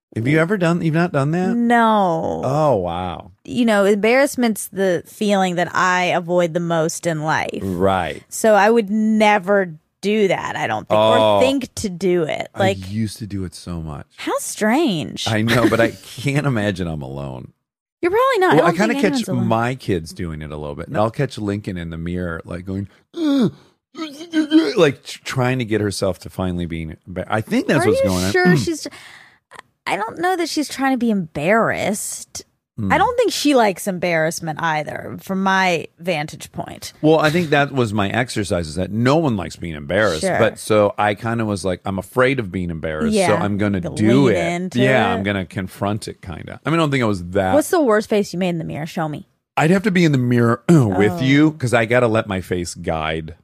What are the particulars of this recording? Armchair Expert Live from Detroit at the Fox Theatre.